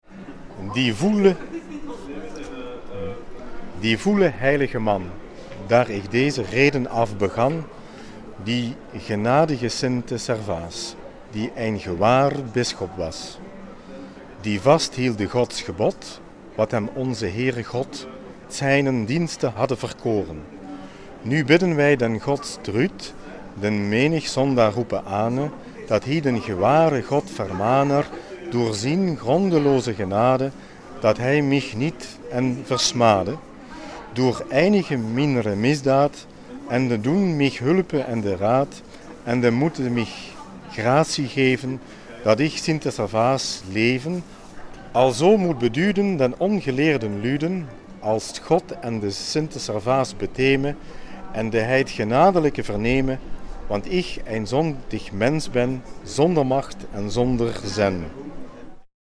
30•06•2013 Alden Biesen [B] Dag Oude Muziek: Servaas by Zefiro Torna & Psallentes